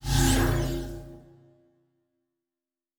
pgs/Assets/Audio/Sci-Fi Sounds/Doors and Portals/Teleport 8_2.wav at master
Teleport 8_2.wav